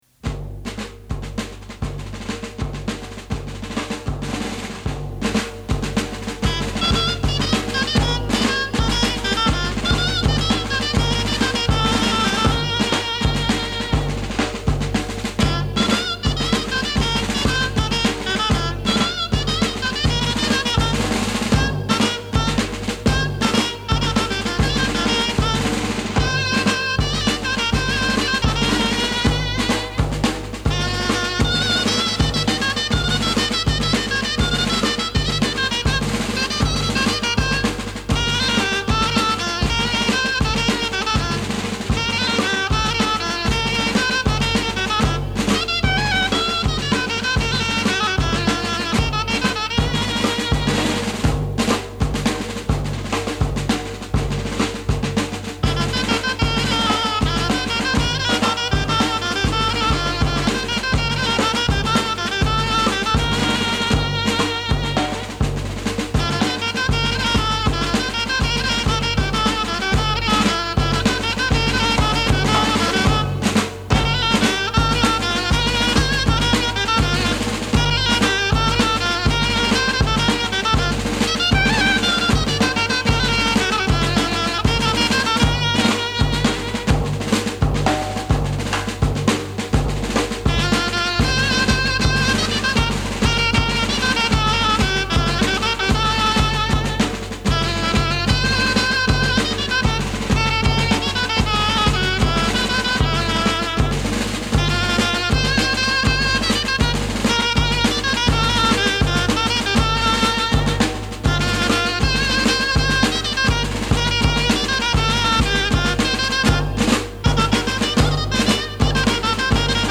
Temas antiguos de música castellana interpretados con la dulzaina.